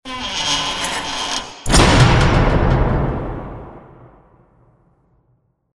Cerrar una puerta de una nave espacial
puerta
Sonidos: Especiales
Sonidos: Fx web